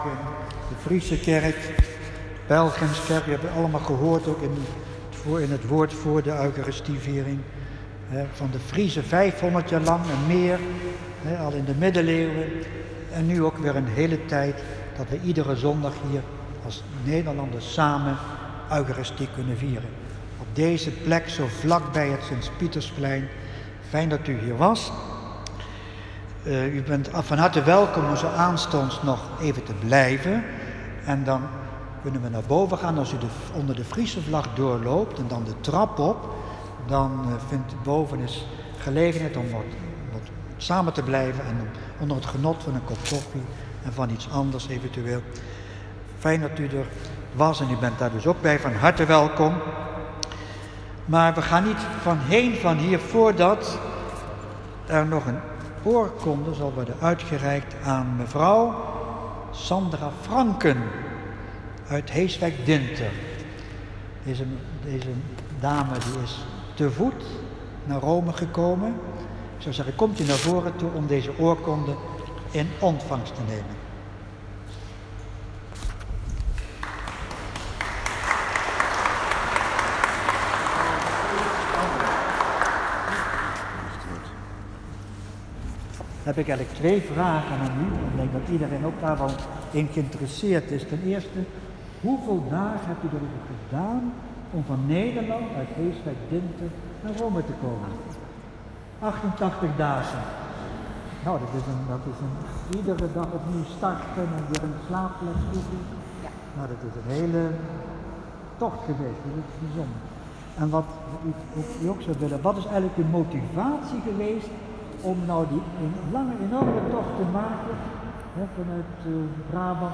slotwoord.m4a